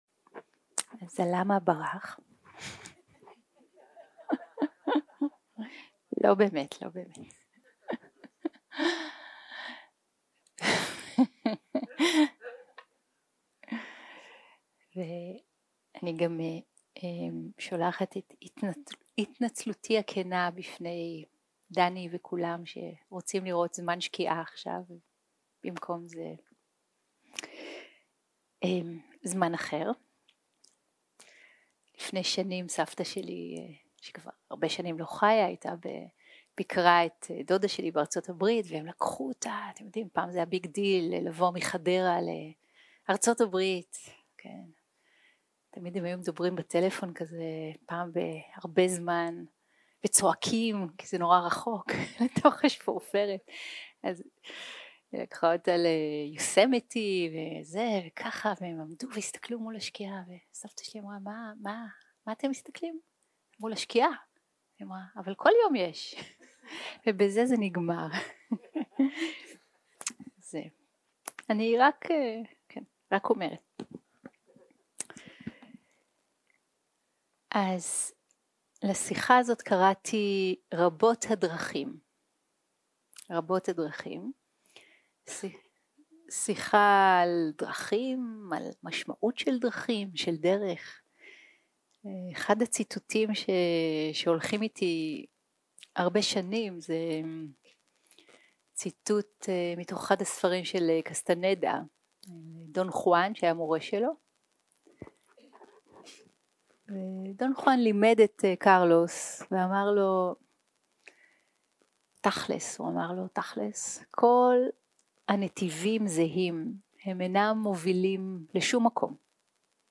יום 4 - הקלטה 17 - ערב - שיחת דהרמה - כל הדרכים מובילות לשומקום 0:00 0:00 סוג ההקלטה: Dharma type: Dharma Talks שפת ההקלטה: Dharma talk language: Hebrew